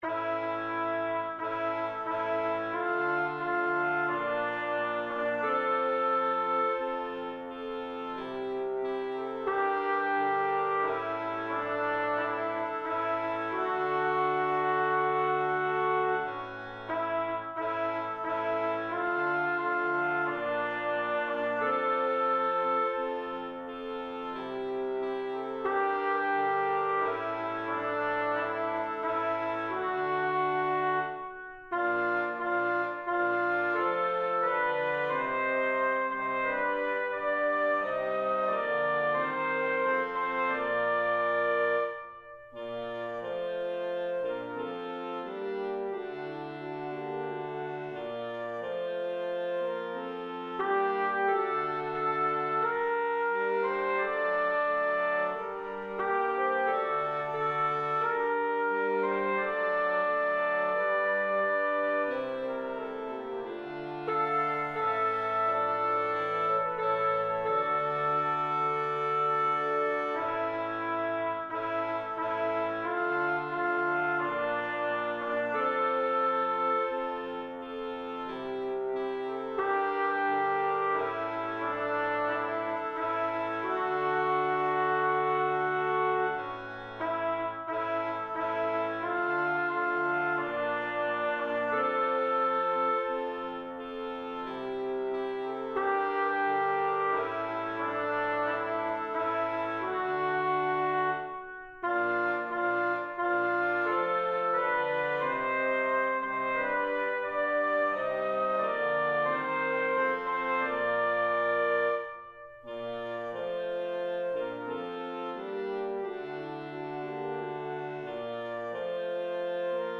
Soprano2
Anthem